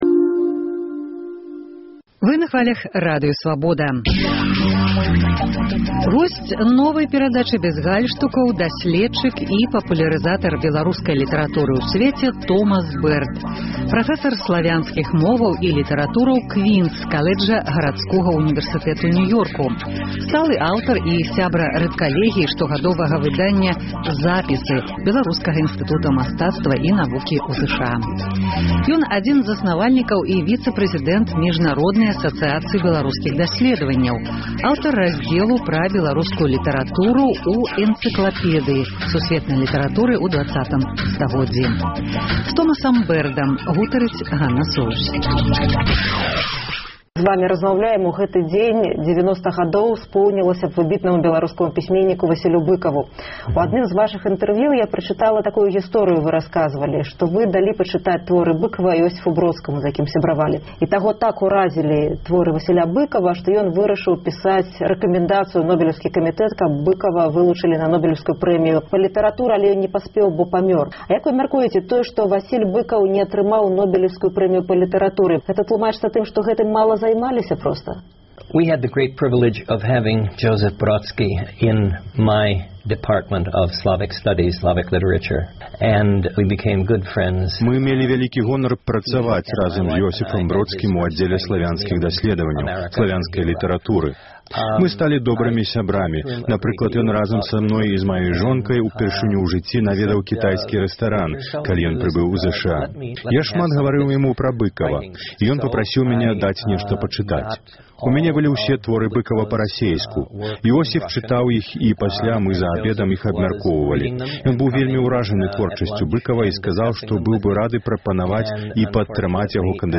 Госьць праграмы